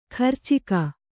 ♪ kharcika